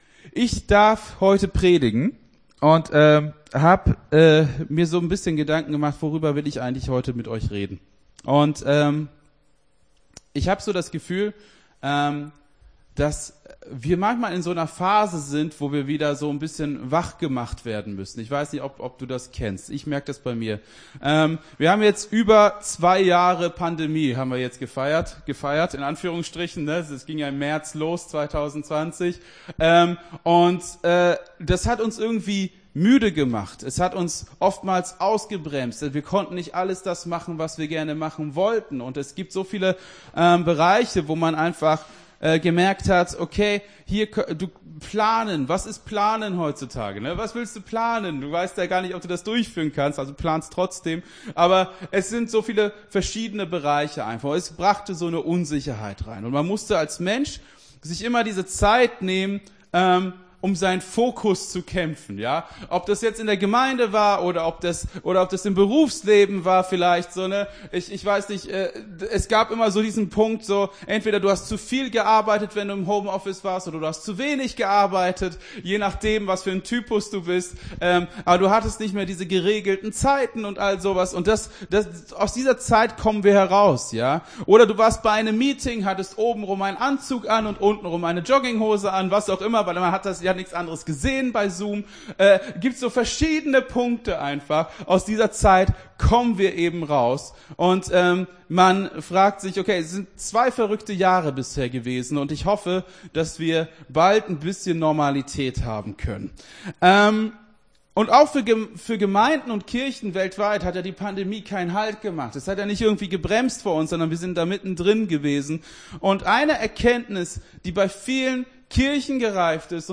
Gottesdienst 27.03.22 - FCG Hagen